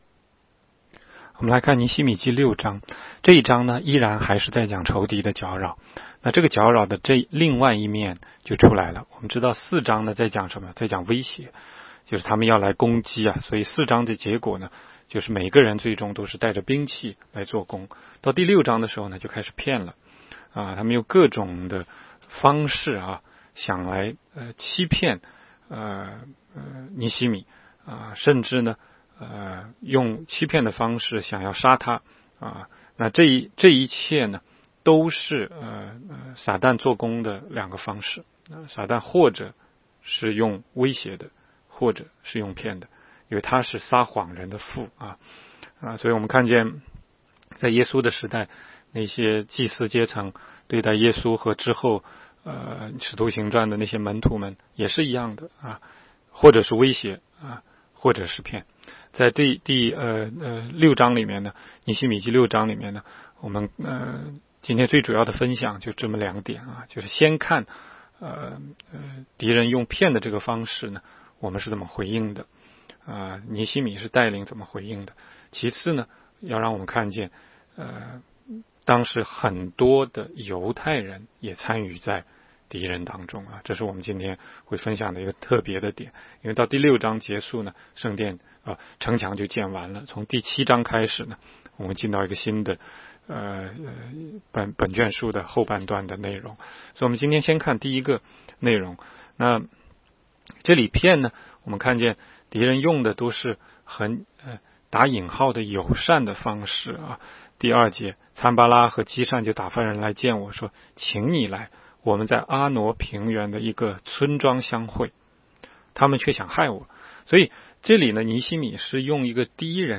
16街讲道录音 - 每日读经-《尼希米记》6章